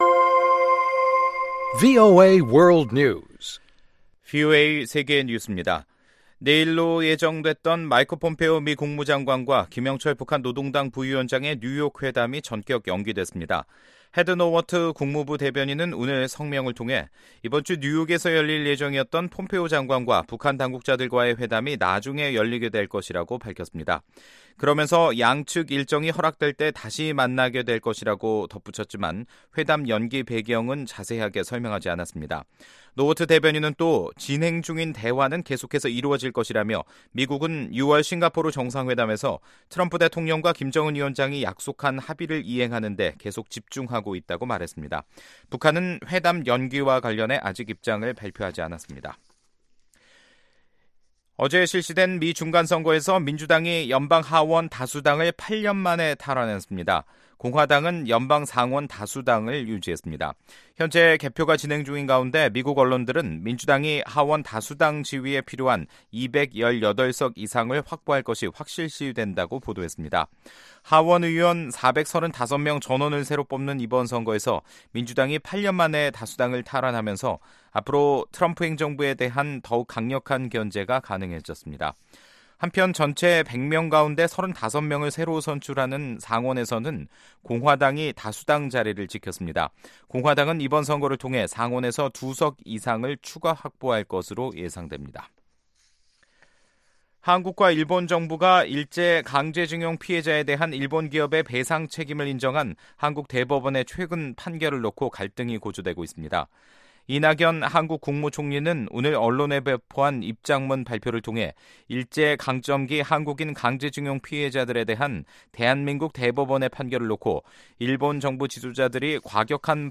VOA 한국어 간판 뉴스 프로그램 '뉴스 투데이', 2018년 11월 7일 2부 방송입니다. 오는 8일로 예정된 마이크 폼페오 미 국무장관과 김영철 북한 노동당 부위원장의 뉴욕회담이 전격 연기됐습니다.